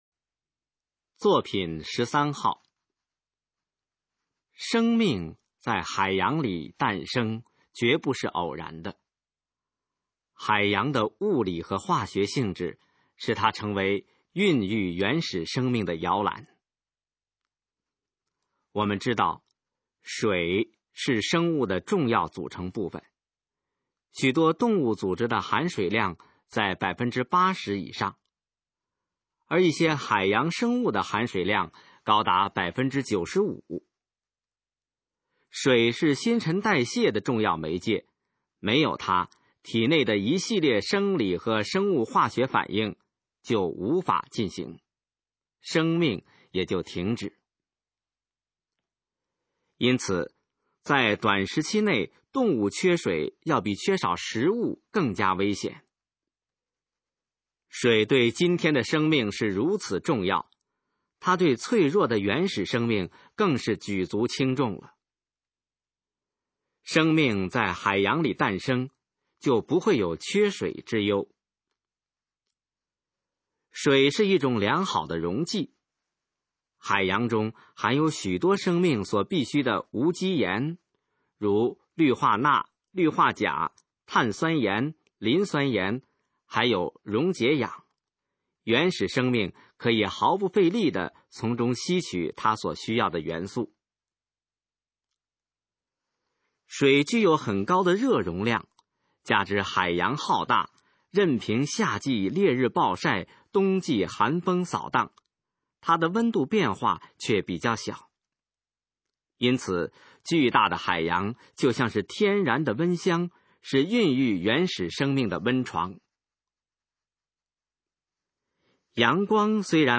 首页 视听 学说普通话 作品朗读（新大纲）
《海洋与生命》示范朗读_水平测试（等级考试）用60篇朗读作品范读　/ 佚名